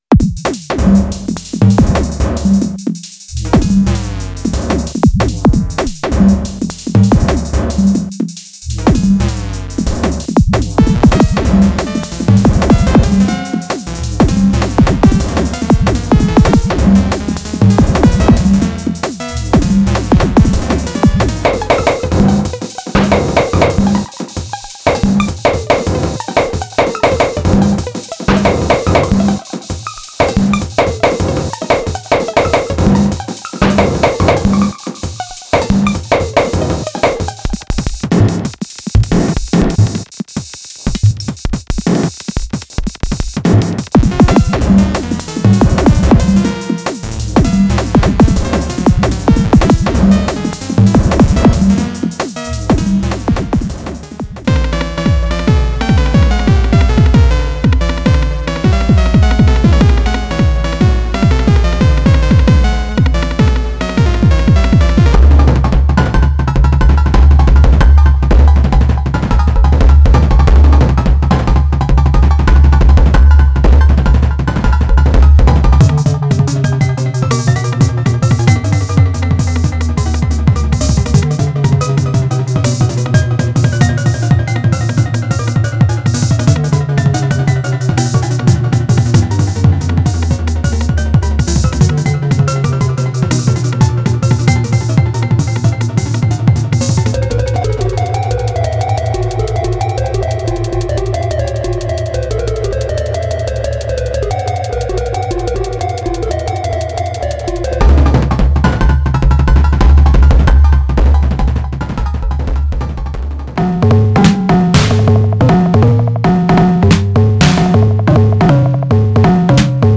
THE ELEKTRON MACHINEDRUM EXPERIENCE IN SAMPLE FORM: FROM IDM TO TECHNO
A vast collection of 987 kicks, snares, percussive elements, glitches, and synth sounds crafted from the Elektron Machinedrum.
Inside you’ll find a massive range of kicks, snares, percussive hits, glitch elements and synhts, all processed with the Machinedrum’s distinctive digital character.
Each engine contributes its own flavor — from punchy analog-style hits and metallic FM tones to physical-modeled resonances and lo-fi 12-bit grit.
MACHINEDRUM-SAMPLE-PACK-AUDIO.mp3